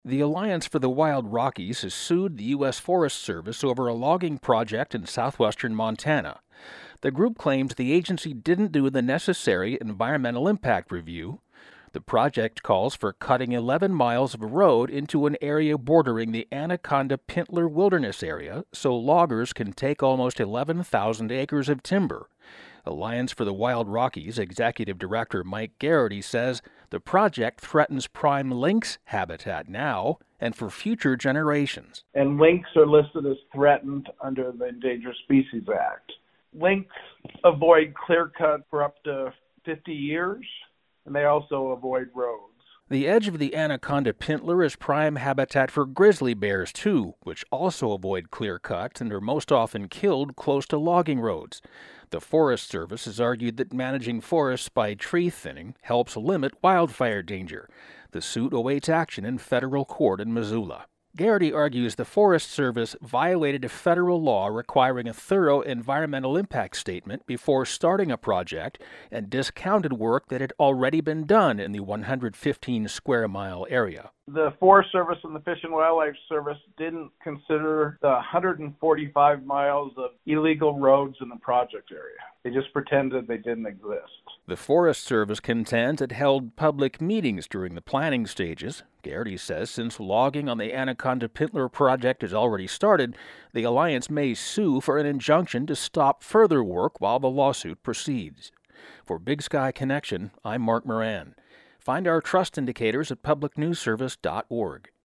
Big Sky Connection - Environmental advocates have sued to stop a massive logging project in southwestern Montana. Work is already underway on 73,000 acres near the Anaconda-Pintler (like "HINT" ler) Wilderness Area.